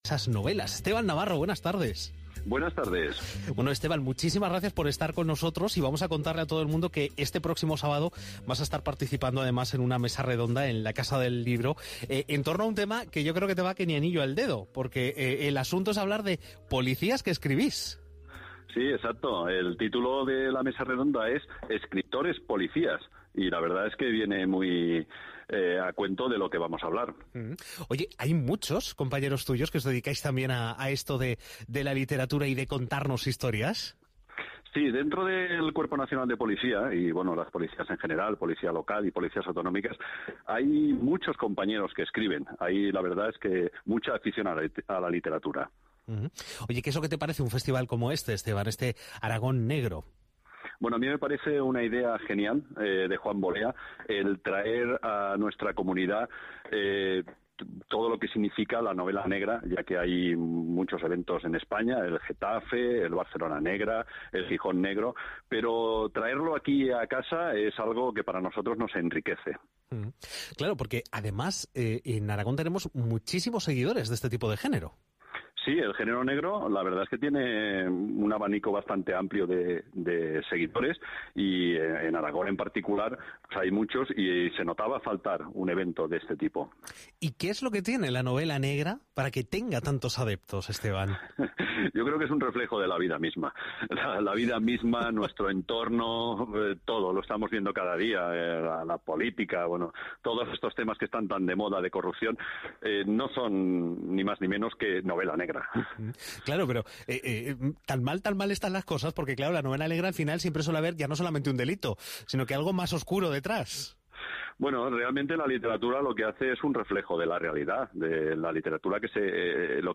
Entrevista en Arag�n Radio acerca de Arag�n Negro